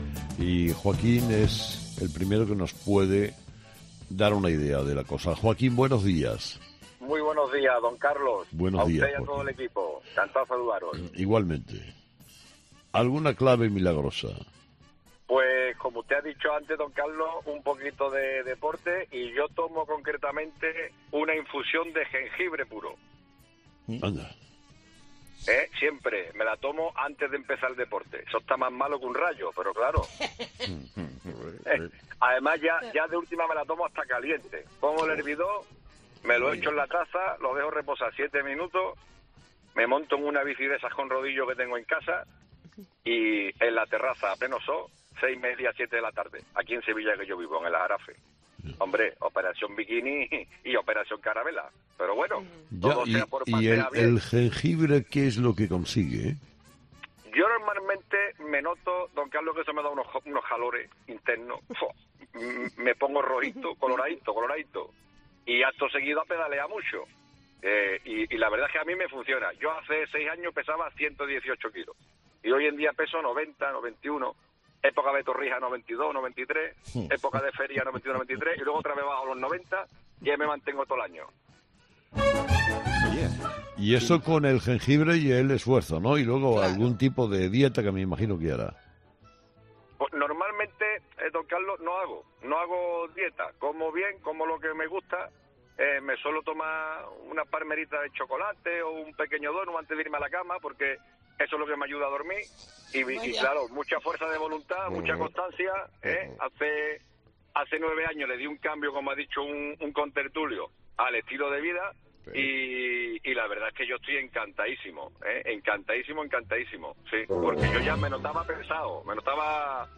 Los fósforos de 'Herrera en COPE' le contaban a Carlos Herrera cómo han afrontado la operación bikini para lucir 'tipín' este verano
En esta ocasión, nos centramos en una charla de Carlos Herrera con los fósforos, hablando de la operación bikini y métodos para adelgazar.